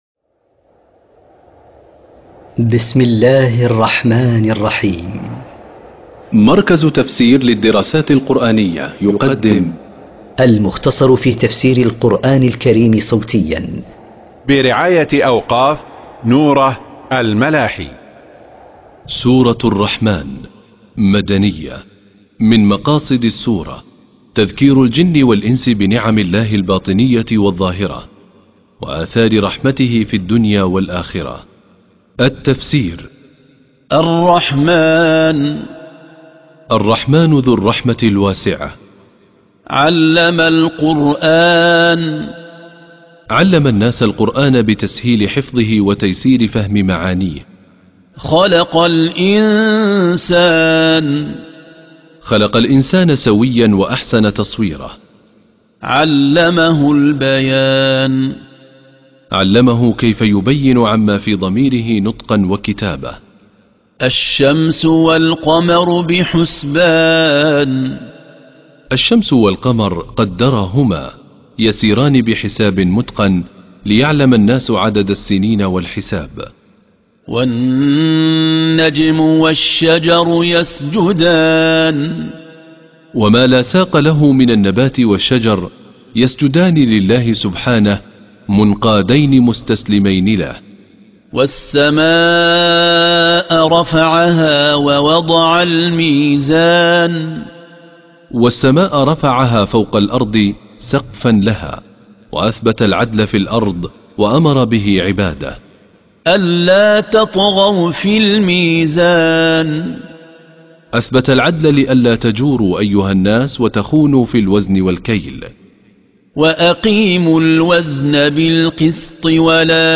قراءة تفسير سورة الرحمن من كتاب المختصر